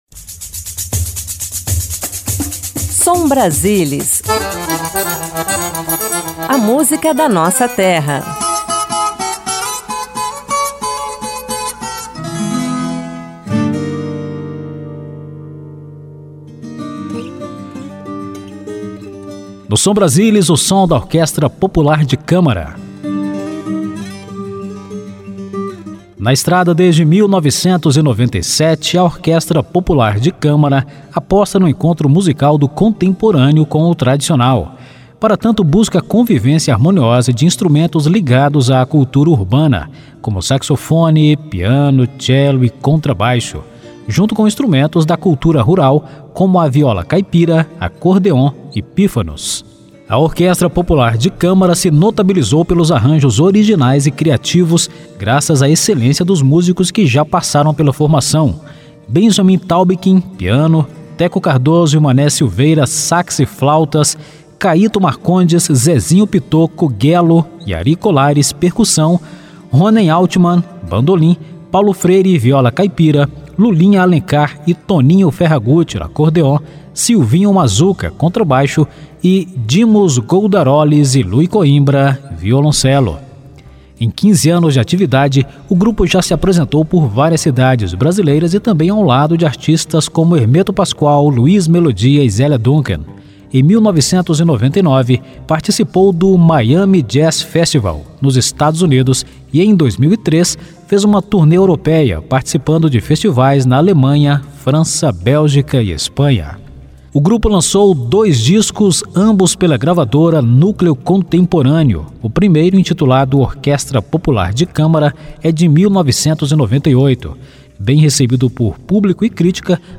Música Brasileira
Música de Câmara
Música popular
MPB
Ritmos brasileiros
Ao vivo